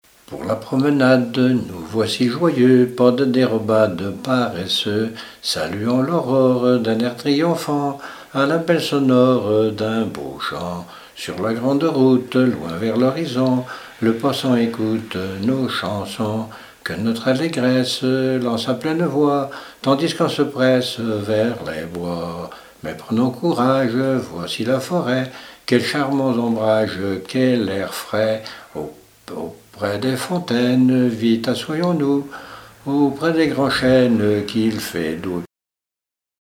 Genre strophique
Répertoire de chants brefs pour la danse
Pièce musicale inédite